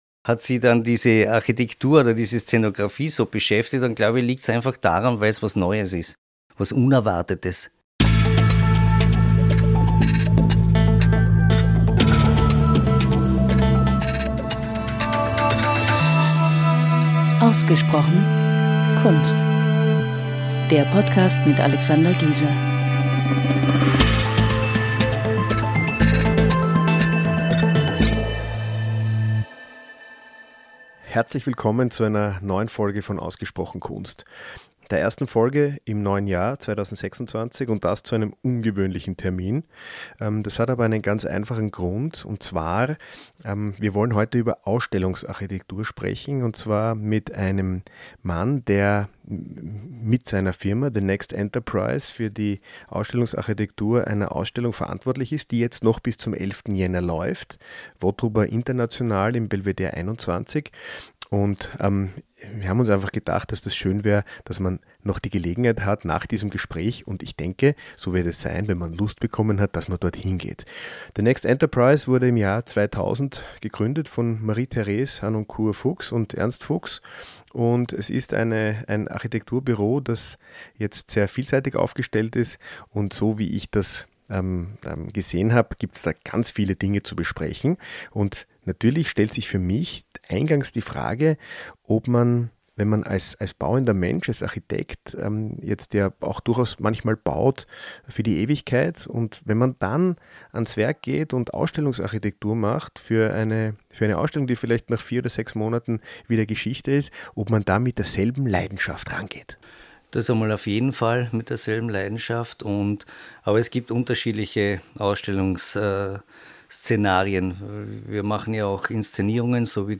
Im Gespräch mit The Next Enterprise ~ Ausgesprochen Kunst Podcast